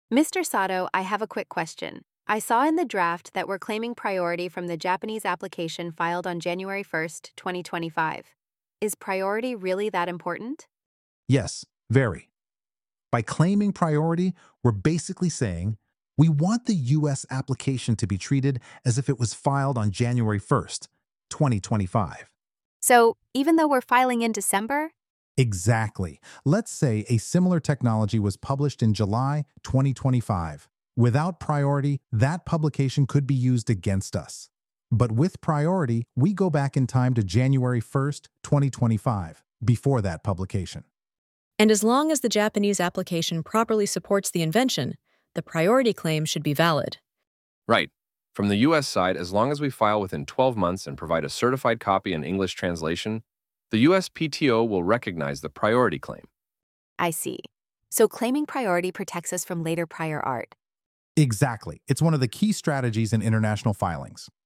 実践ダイアログの英語音声